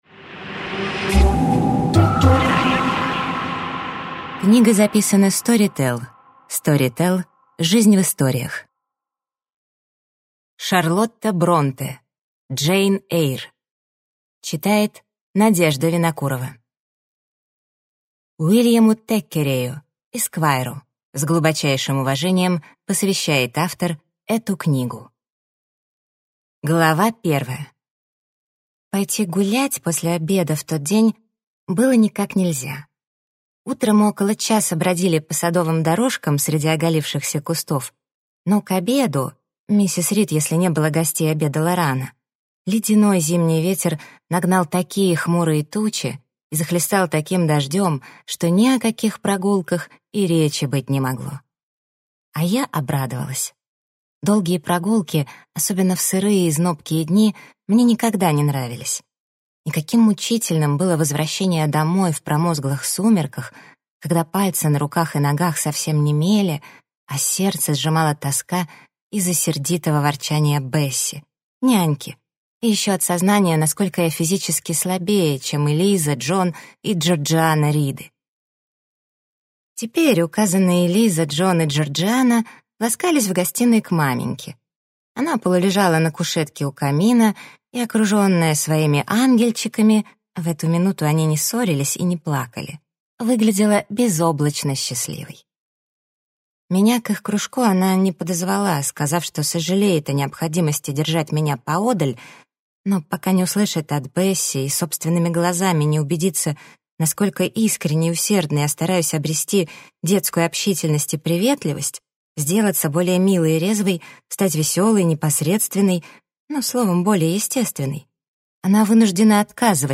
Аудиокнига Джейн Эйр | Библиотека аудиокниг